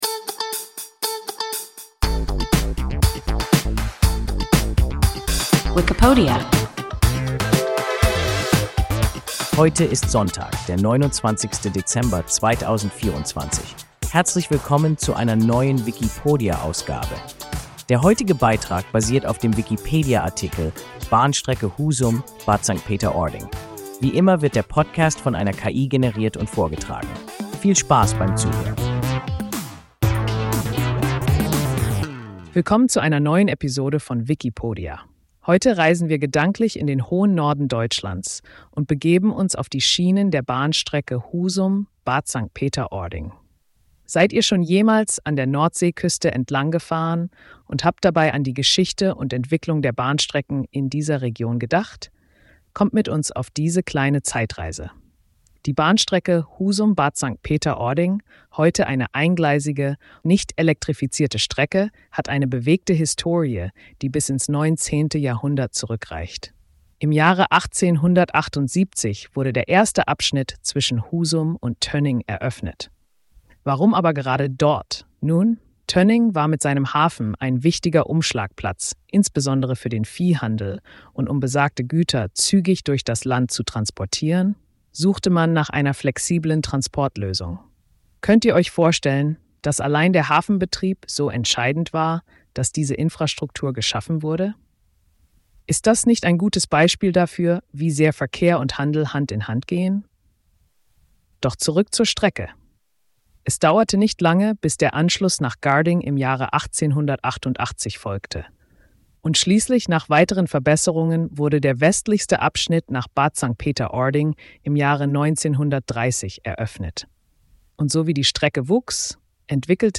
Bahnstrecke Husum–Bad St. Peter-Ording – WIKIPODIA – ein KI Podcast